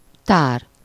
Ääntäminen
Île-de-France, France: IPA: [œ̃ ma.ɡa.zɛ̃]